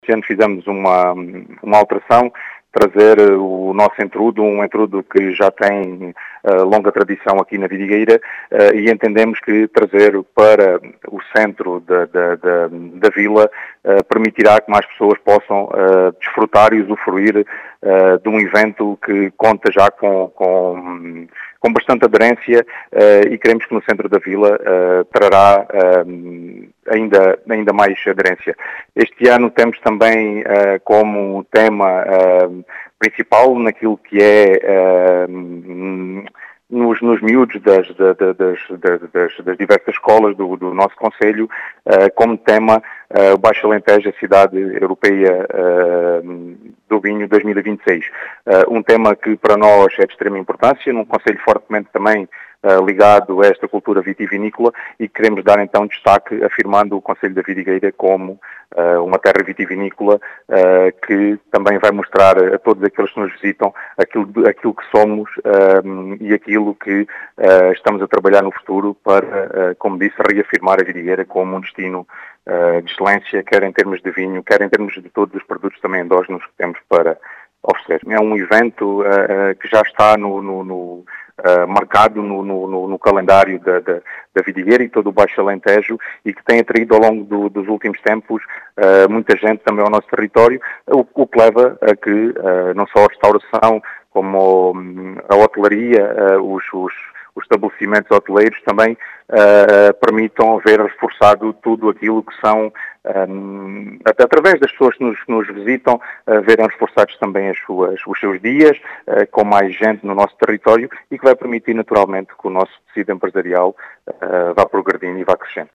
As explicações são do presidente da Câmara de Vidigueira, Ricardo Bonito,  que quis trazer o Entrudo para o centro da vila, na procura de uma “maior aderência”.